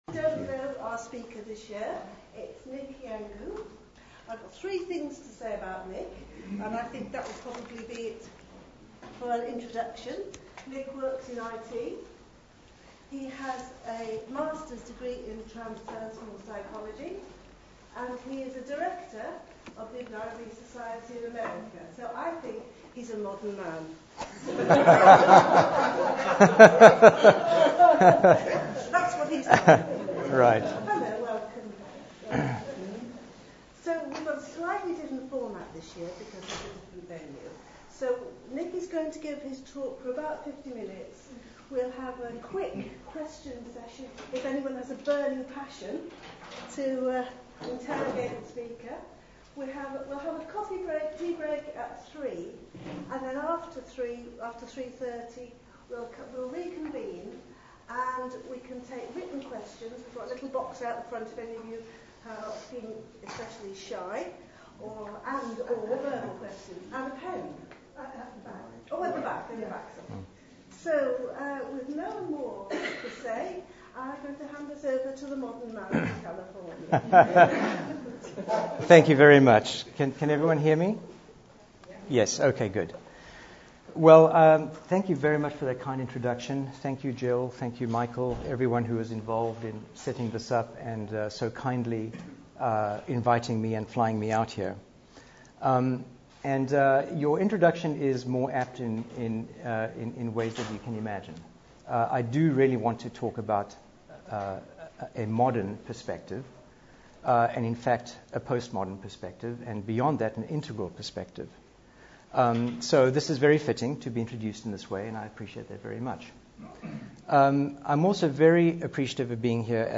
The Beshara Lecture is an annual lecture held in London, Edinburgh and at the Beshara School in the Scottish Borders. The aim of the Beshara Lecture is to explore different fields of knowledge from a perspective of self knowledge, unity and our shared humanity.